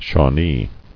[Shaw·nee]